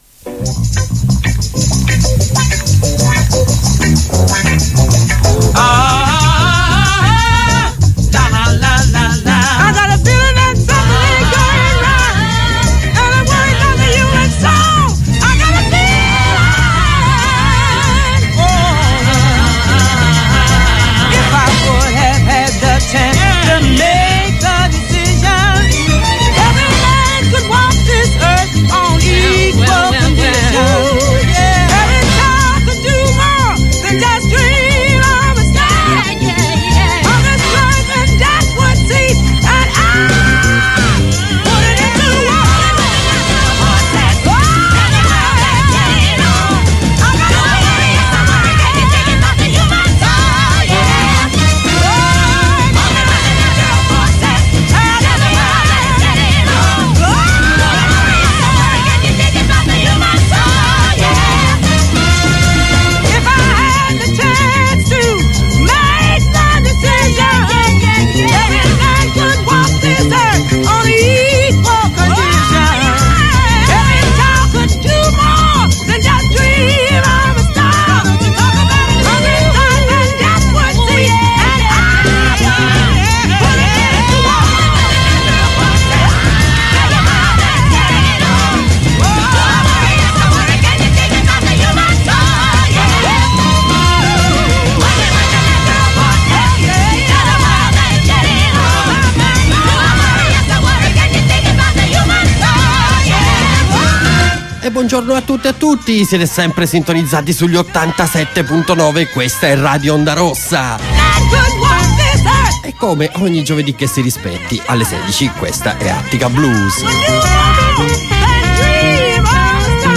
jazz | Radio Onda Rossa